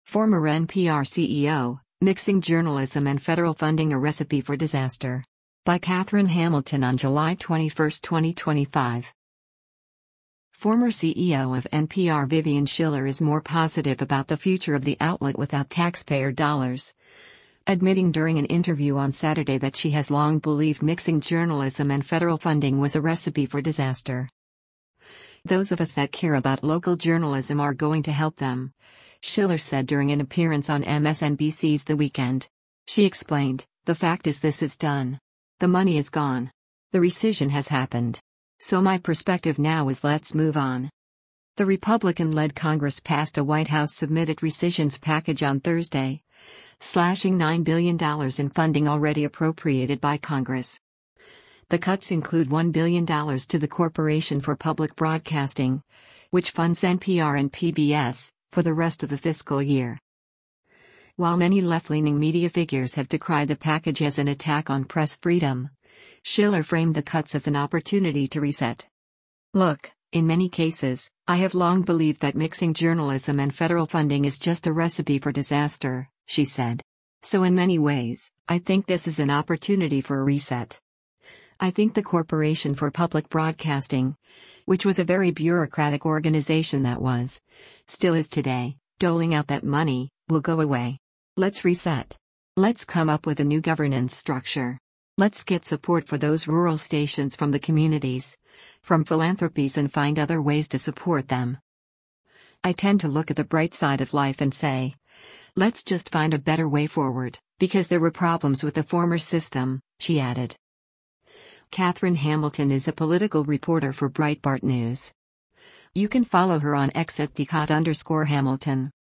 Former CEO of NPR Vivian Schiller is more positive about the future of the outlet without taxpayer dollars, admitting during an interview on Saturday that she has long believed mixing journalism and federal funding was a “recipe for disaster.”